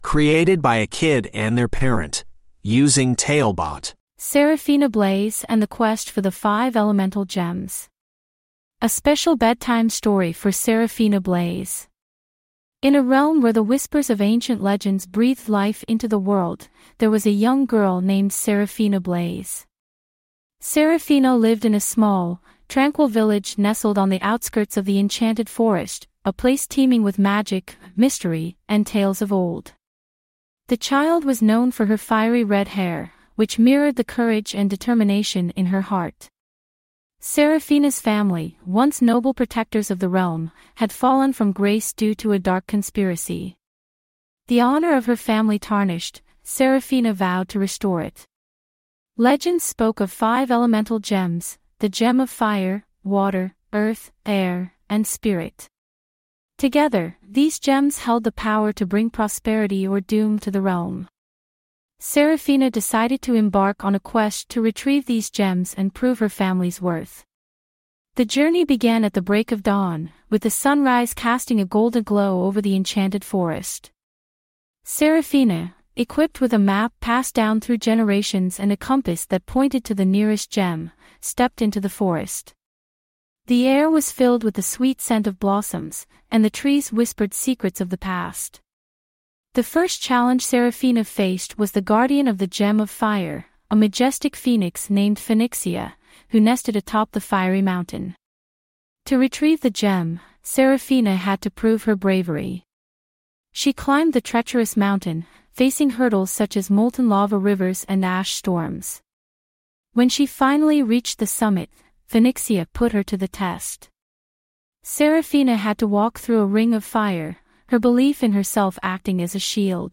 TaleBot Bedtime Stories